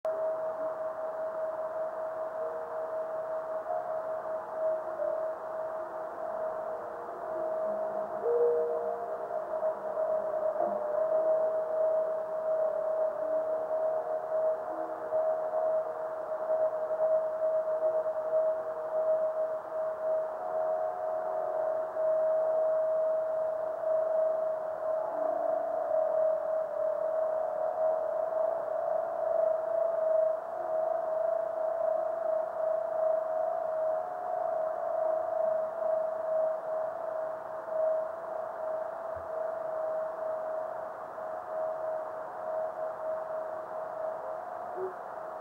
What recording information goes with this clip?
video and stereo sound: